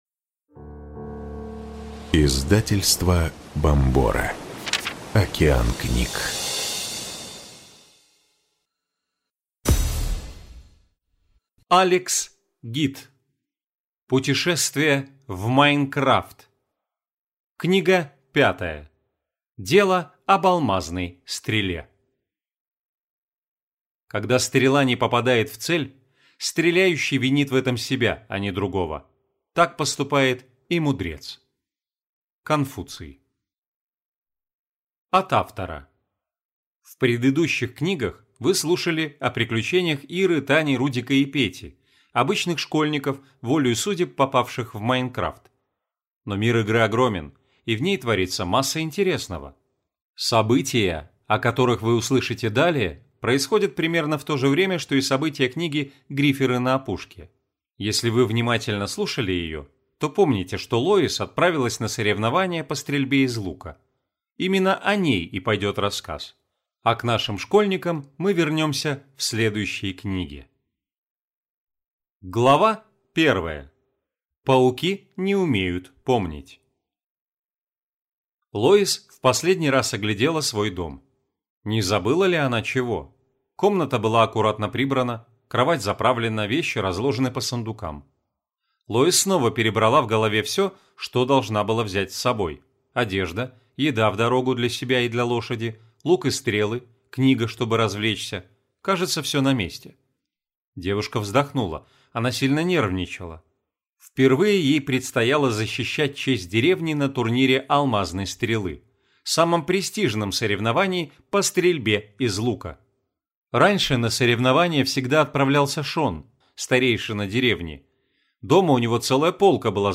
Aудиокнига Дело об алмазной стреле